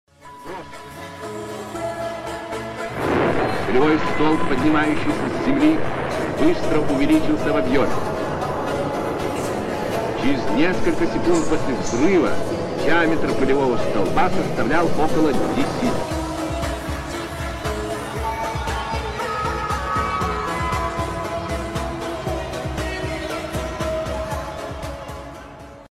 Bom Hidrogen Mematikan Milik Rusia sound effects free download
Mp3 Sound Effect Bom Hidrogen Mematikan Milik Rusia 🥶.